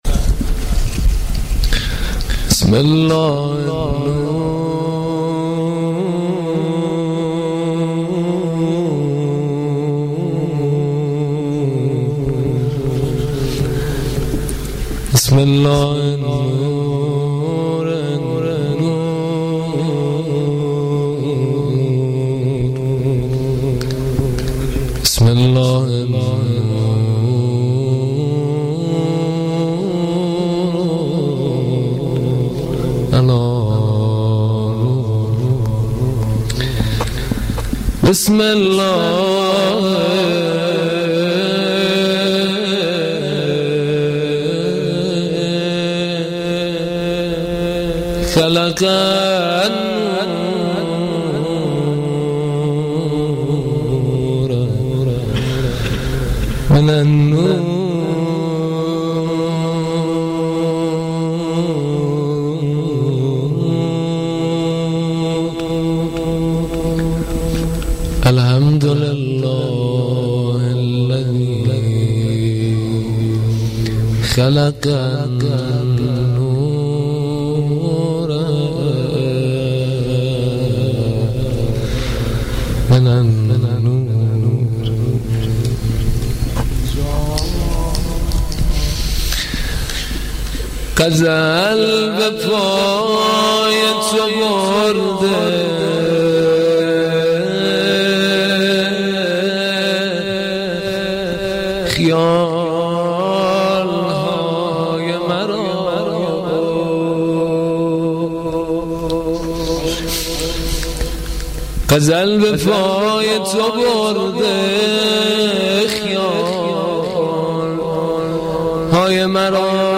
• شعرخوانی
مداحی
هیئت دانشجویی